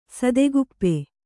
♪ sadeguppe